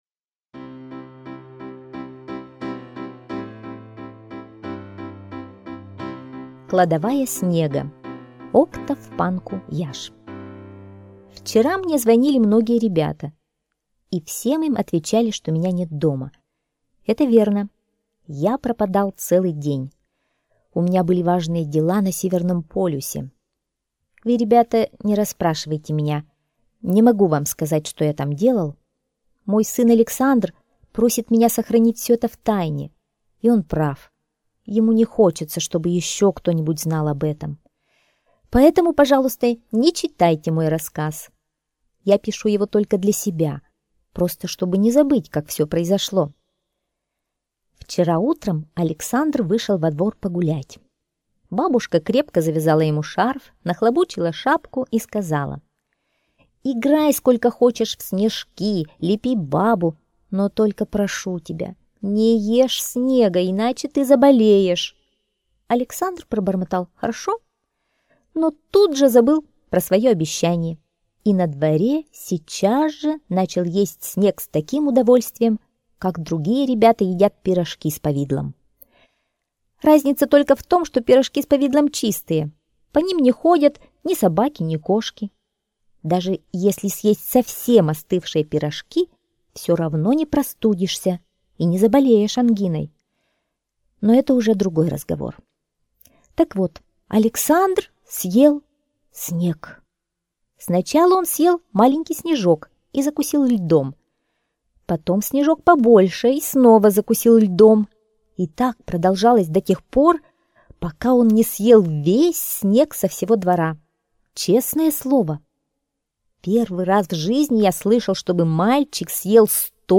Кладовая снега - аудиосказка Панку-Яшь О. Мальчик Александр съел весь снег в городе и ребята не могли кататься на лыжах и санках...